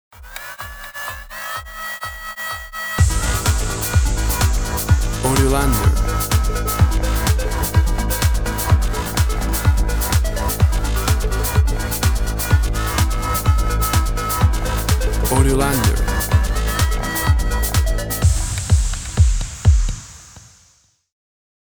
WAV Sample Rate 16-Bit Stereo, 44.1 kHz
Tempo (BPM) 125